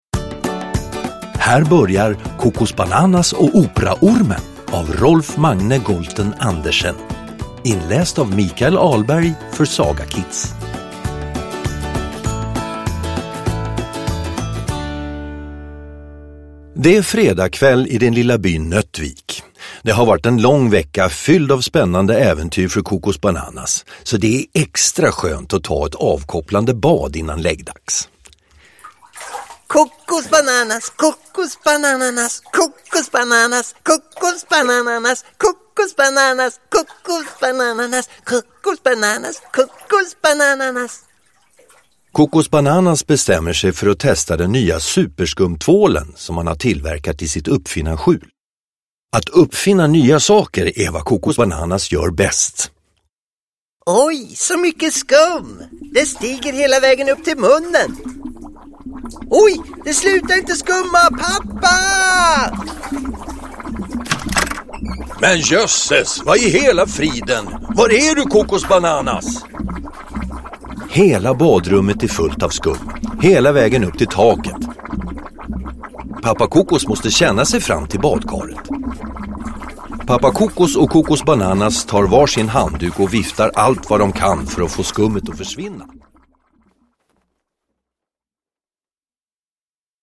Ljudbok
Med härliga ljudeffekter och musik bjuder Kokosbananas på underhållning för hela familjen!